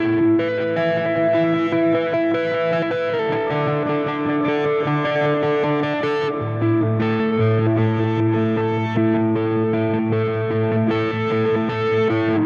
Spaced Out Knoll Electric Guitar 03b.wav